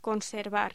Locución: Conservar
voz